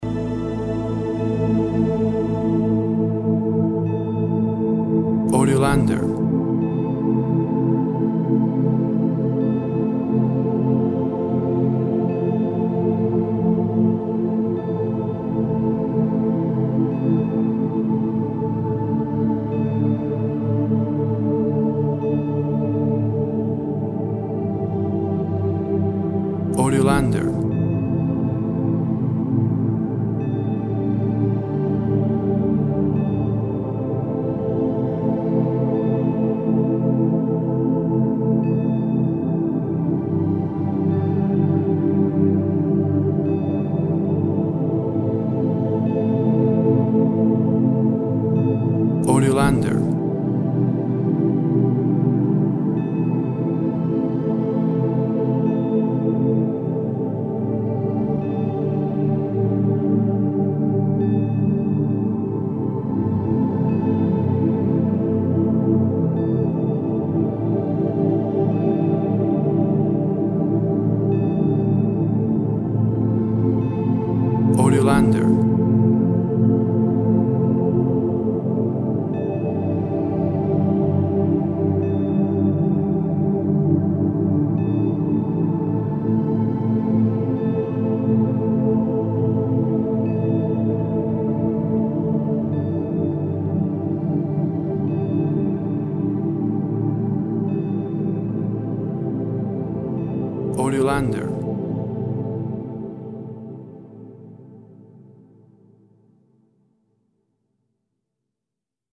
Soft persistent and hypnotic synth sounds.
Tempo (BPM) 54